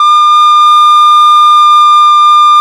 SOP REC-D5.wav